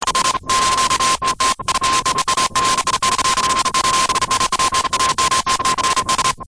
Blanketing sound